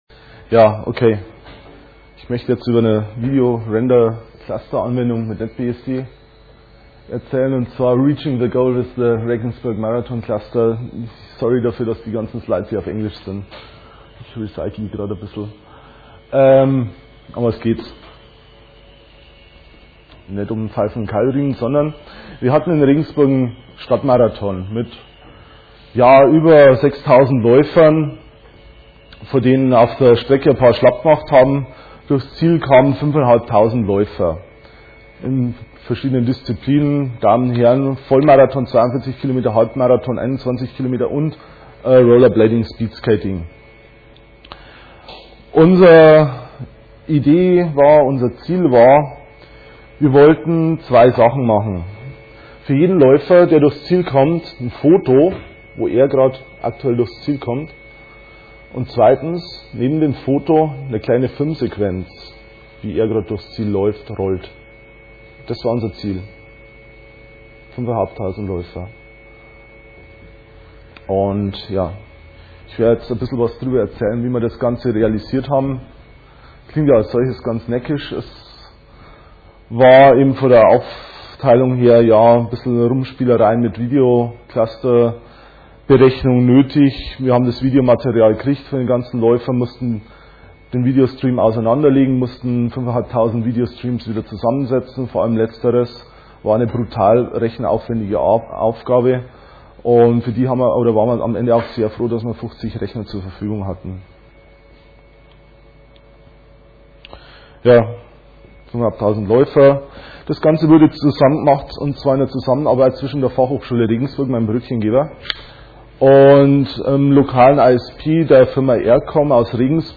5. Chemnitzer Linux-Tag
Sonntag, 16:00 Uhr im Raum V1 - NetBSD